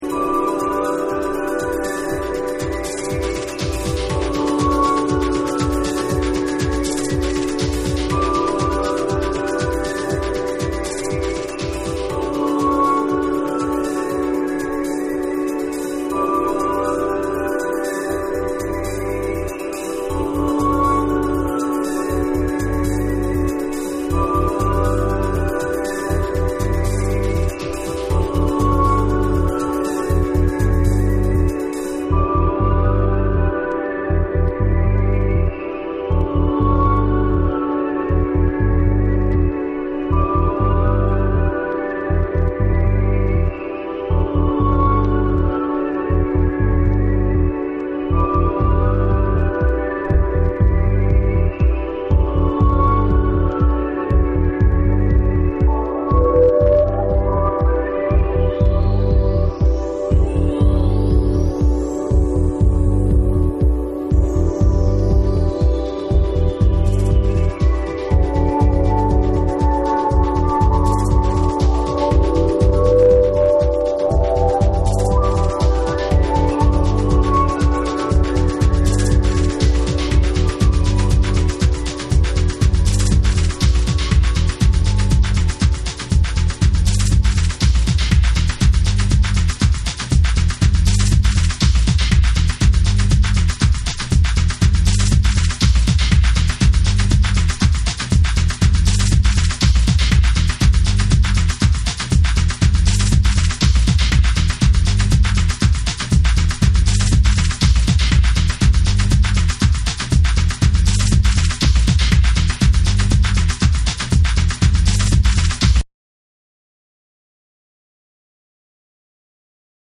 TECHNO & HOUSE